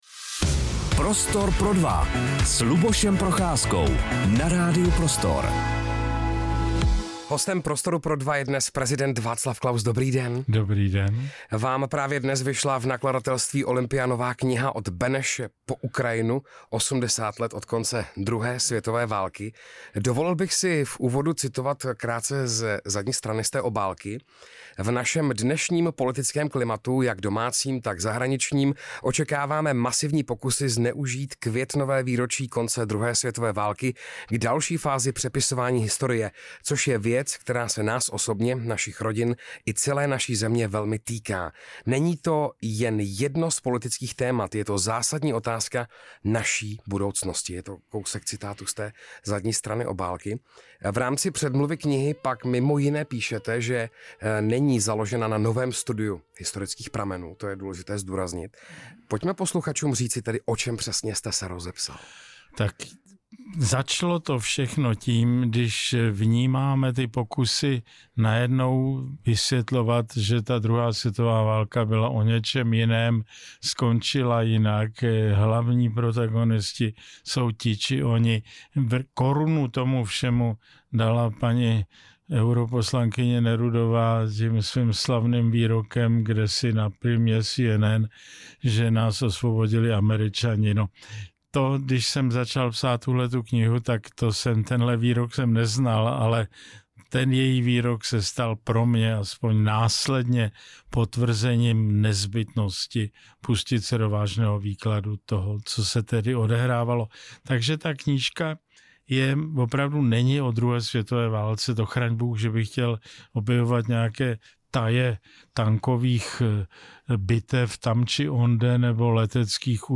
Rozhovor s Václavem Klausem | Radio Prostor